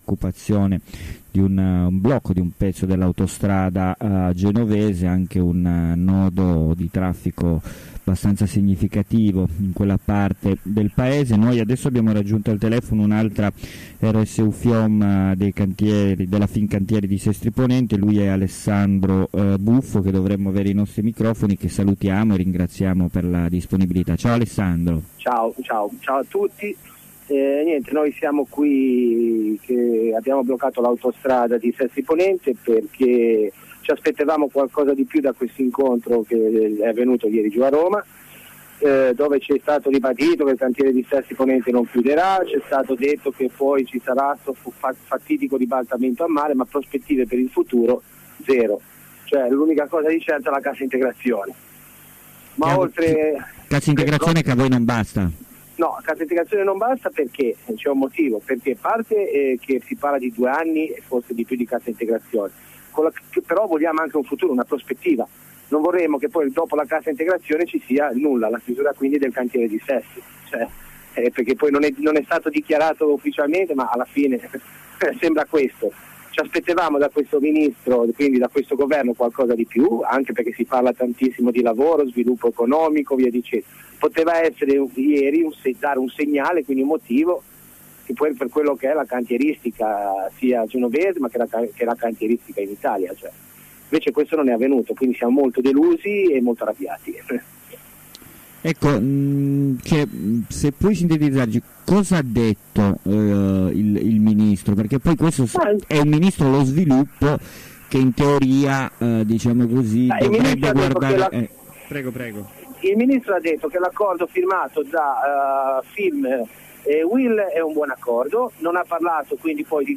Ascolta la diretta con un lavoratore della Fincantieri e rsu-fiom